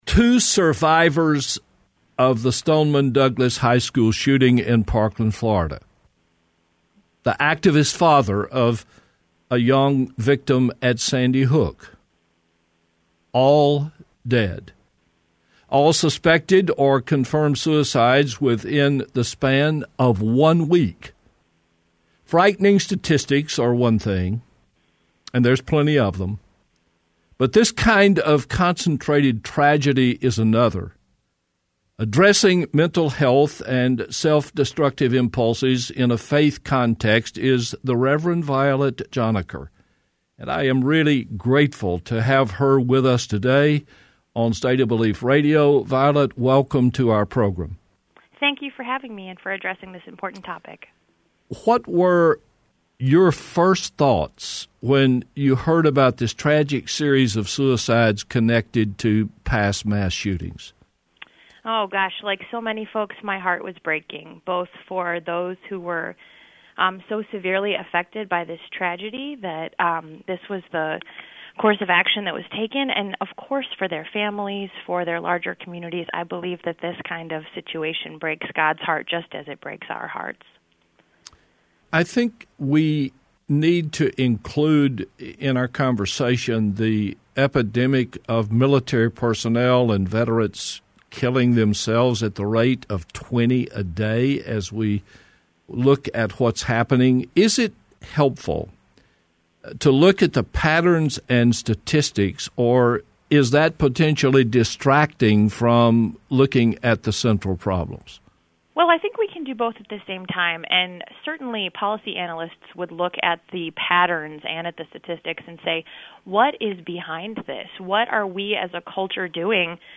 Hear the full March 30, 2019 State of Belief Radio program here.